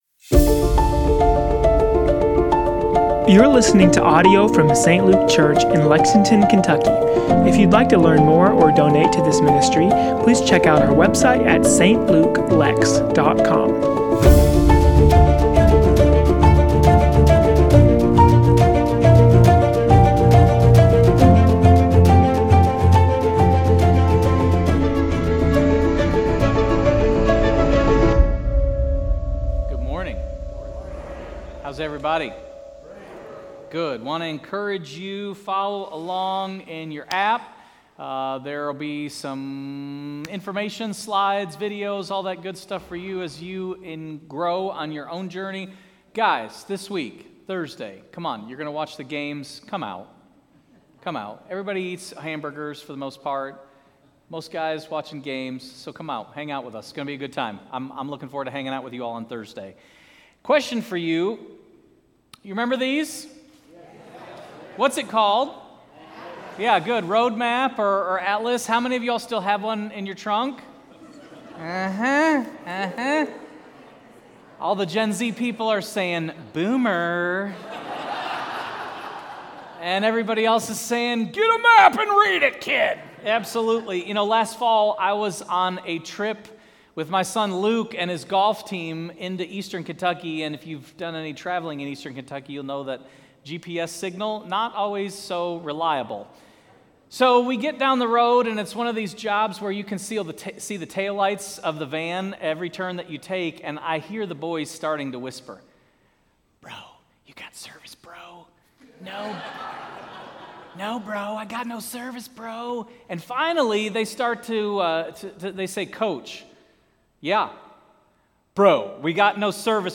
Sermon Series: Hebrews: Rediscovering Jesus